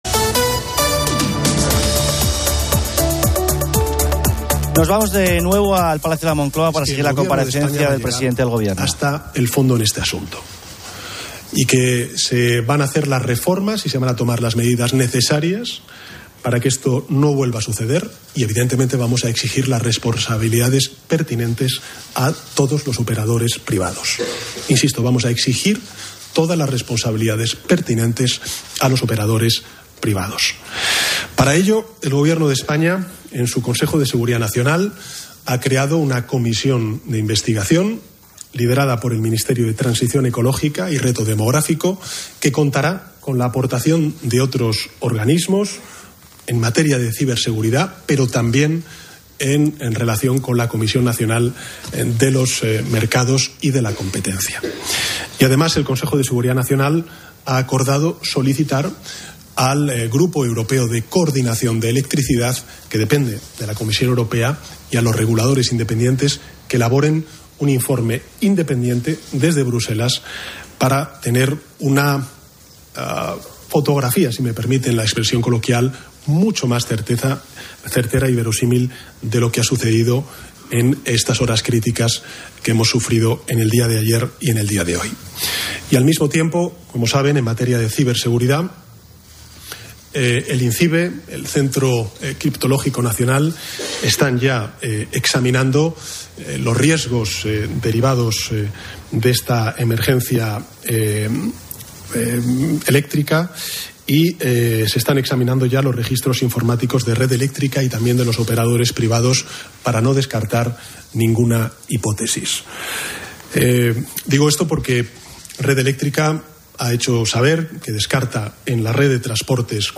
Nos vamos de nuevo al Palacio de la Moncloa para seguir la comparecencia del presidente del Gobierno.